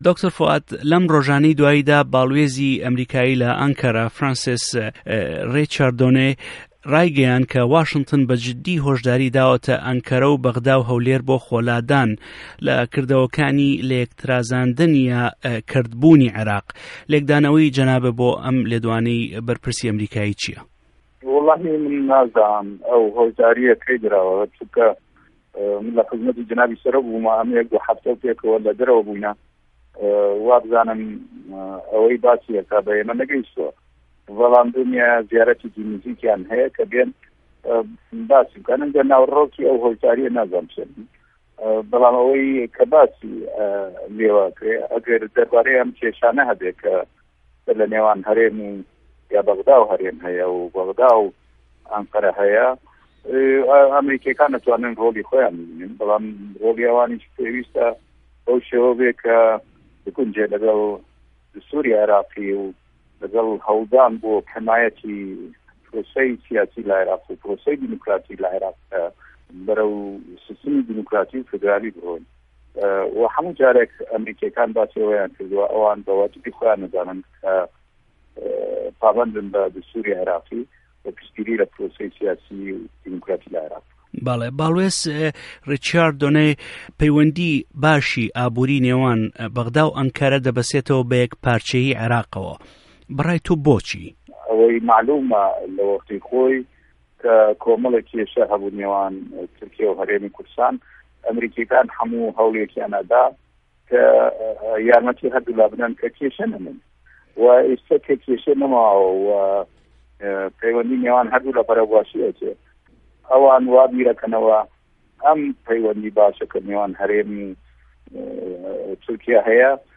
وتووێژی دکتۆر فوئاد حسێن